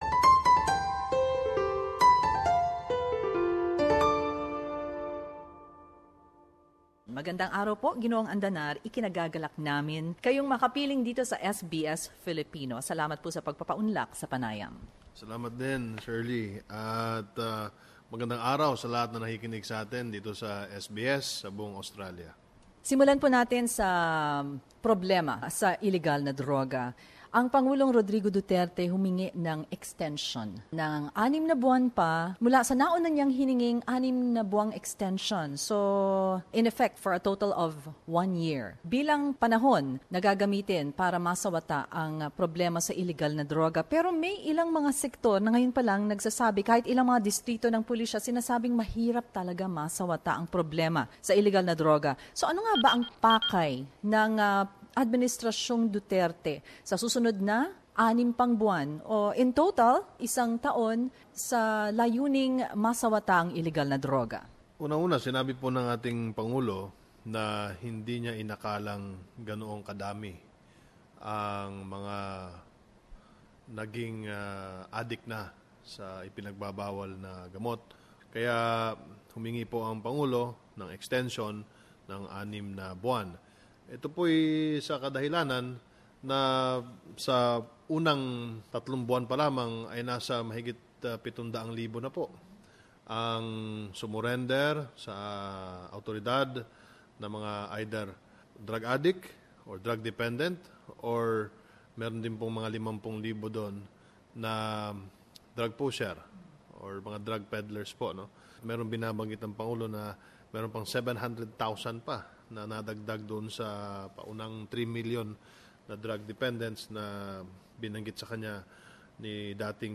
PCO Secretary Martin Andanar interviewed